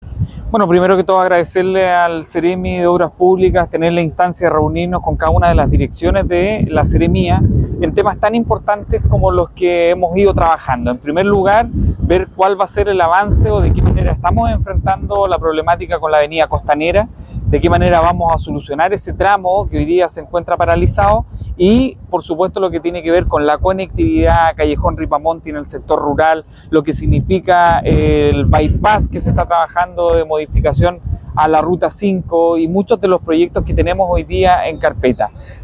Respecto a la reunión, el alcalde de Coquimbo, Alí Manouchehr, señaló que
Ali-Manouchehri.mp3